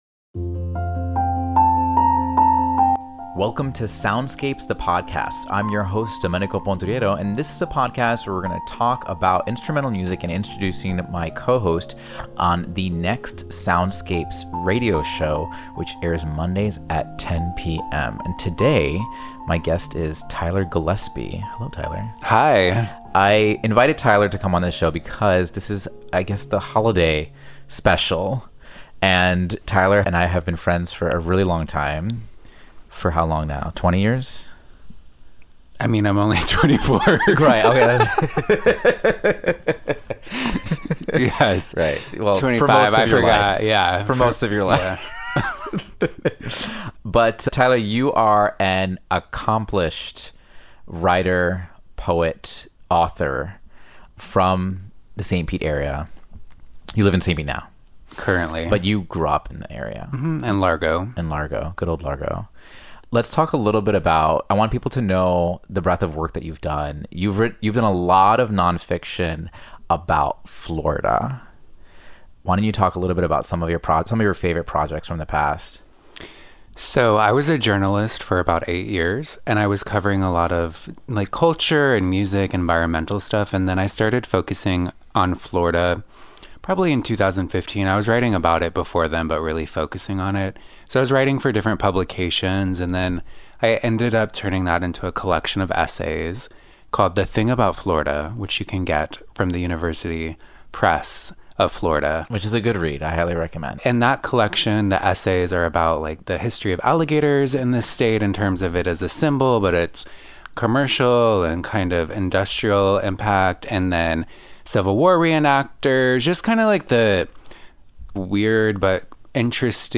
🎧 A thoughtful, playful conversation about music, creativity, and finding joy during the holidays.